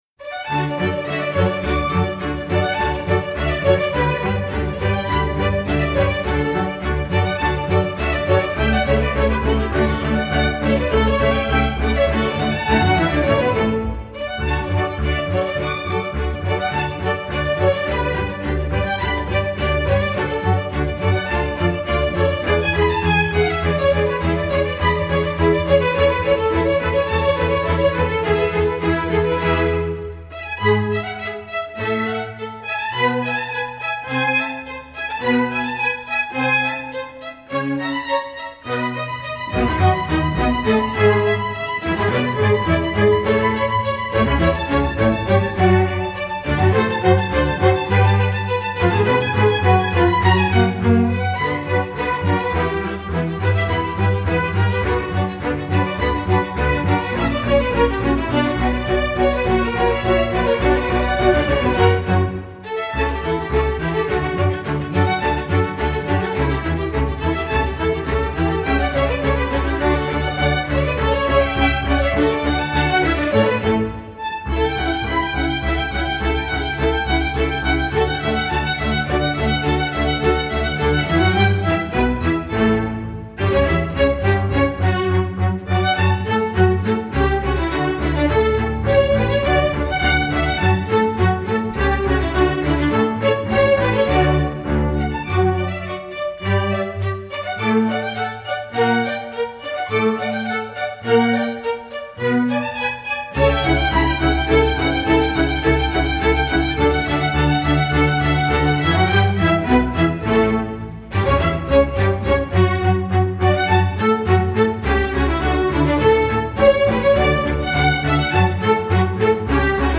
masterwork arrangement, opera